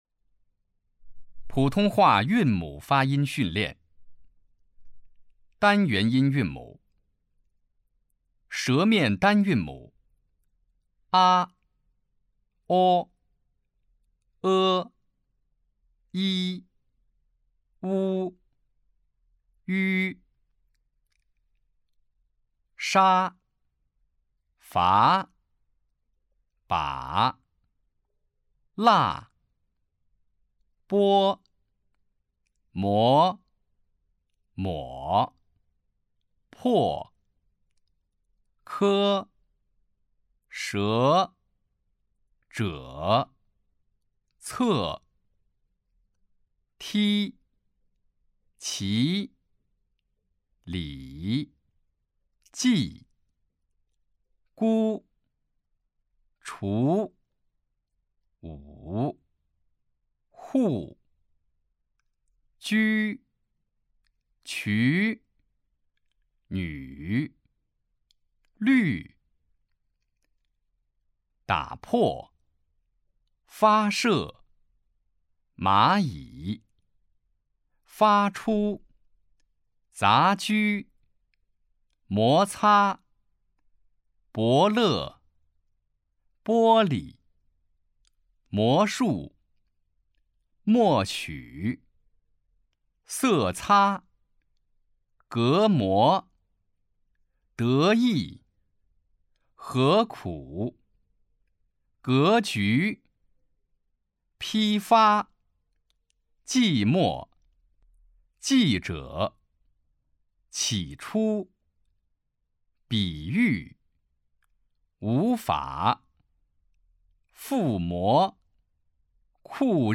普通话水平测试 > 普通话水平测试资料包 > 02-普通话水平测试提升指导及训练音频
003号普通话韵母发音训练.mp3